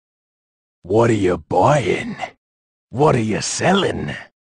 fun-with-quotes-77-what-are-ya-buyin-what-are-ya-sellin-resident-evil-4.mp3